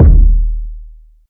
Kicks
KICK.84.NEPT.wav